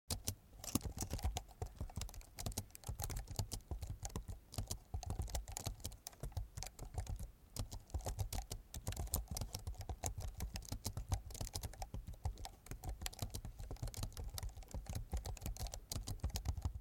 دانلود آهنگ کیبورد 13 از افکت صوتی اشیاء
دانلود صدای کیبورد 13 از ساعد نیوز با لینک مستقیم و کیفیت بالا
جلوه های صوتی